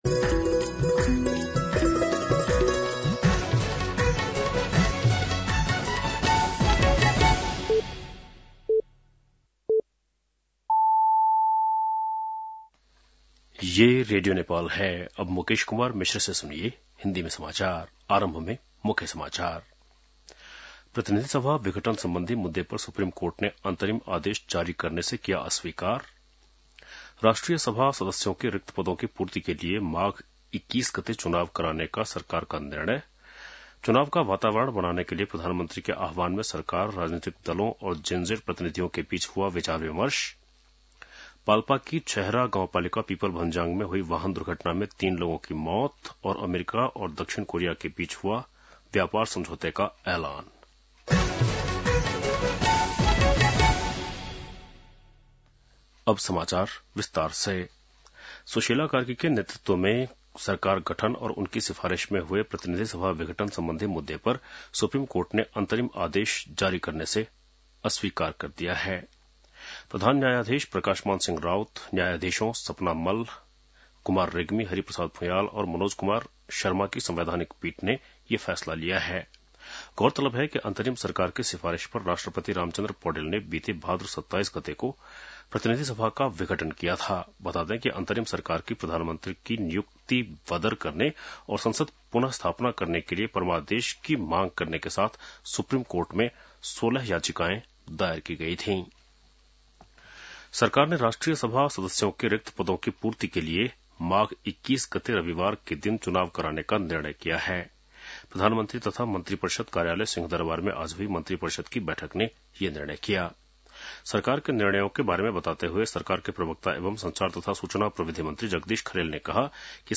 An online outlet of Nepal's national radio broadcaster
बेलुकी १० बजेको हिन्दी समाचार : १२ कार्तिक , २०८२